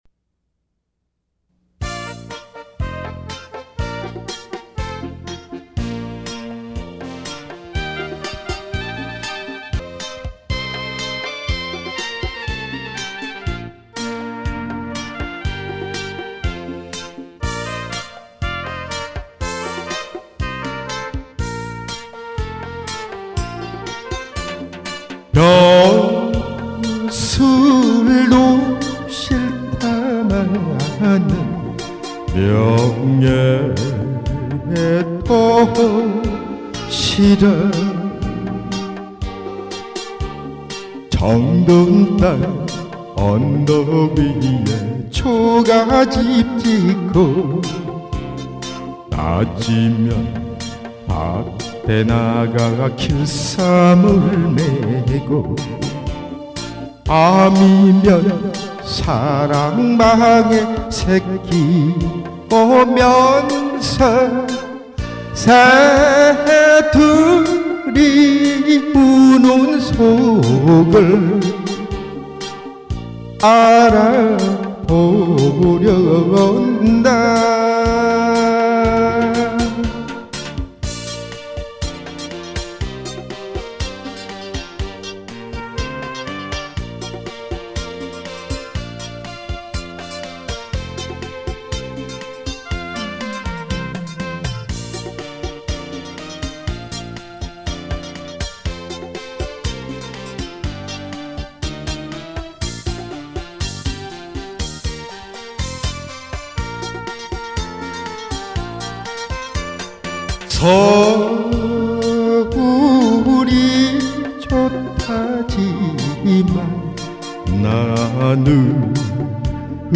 아주 오래전에 불렀던 노래입니다.